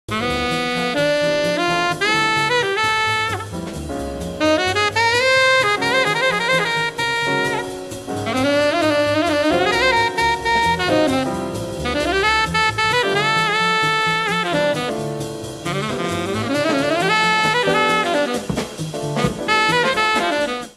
LIVE AT KURHAUS, SCHEVENINGEN, HOLLAND
SOUNDBOARD RECORDING